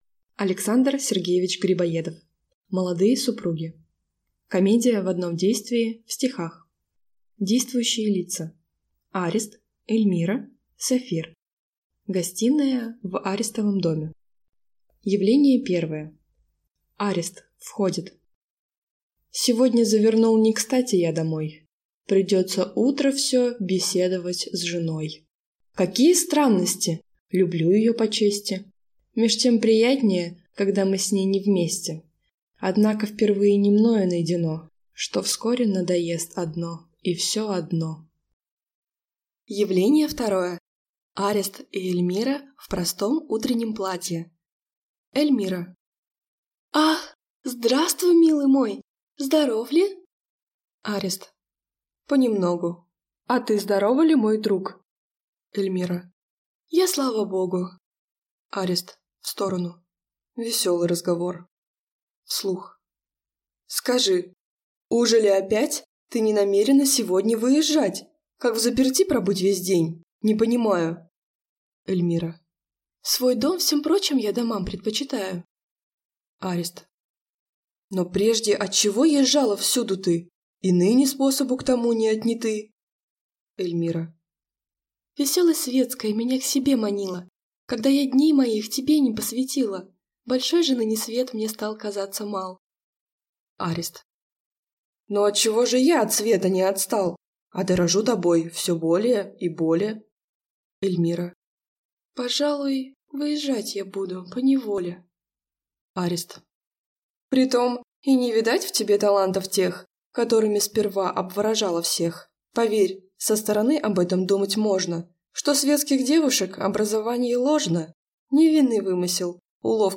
Аудиокнига Молодые супруги | Библиотека аудиокниг